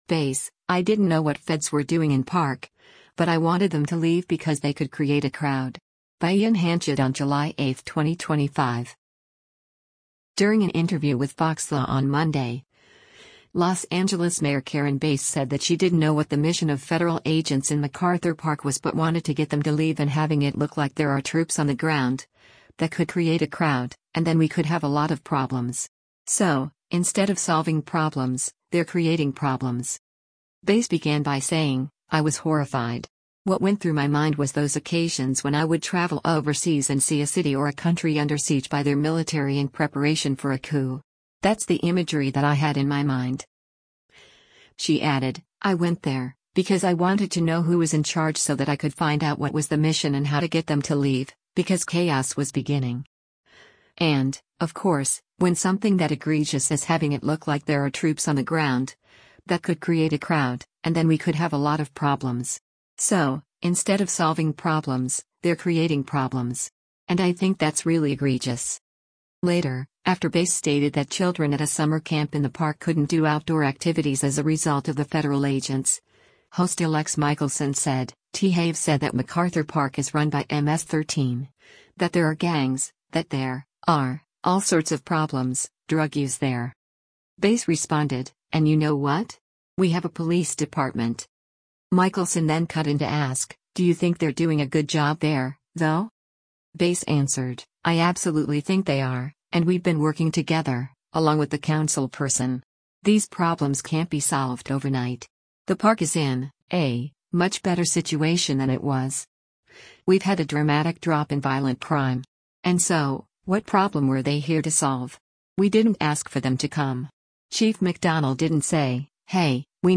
During an interview with FOXLA on Monday, Los Angeles Mayor Karen Bass said that she didn’t know what the mission of federal agents in MacArthur Park was but wanted to get them to leave and “having it look like there are troops on the ground, that could create a crowd, and then we could have a lot of problems. So, instead of solving problems, they’re creating problems.”